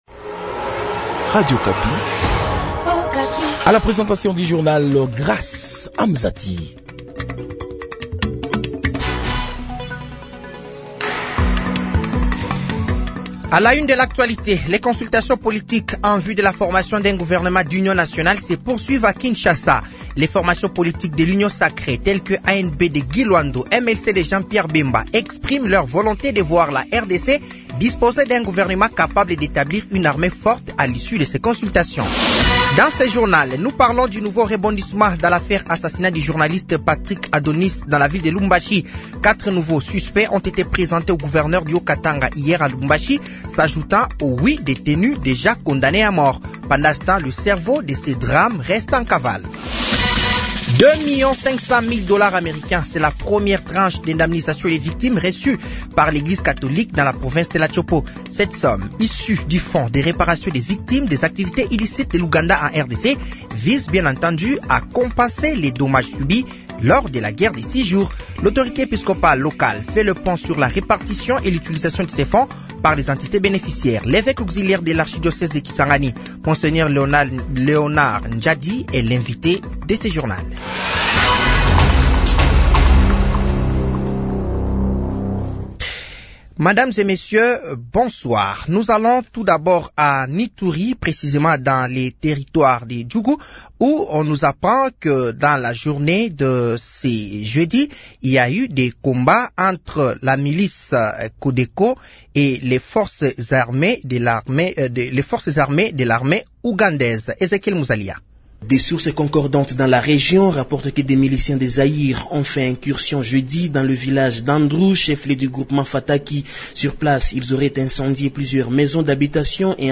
Journal français de 18h de ce vendredi 28 mars 2025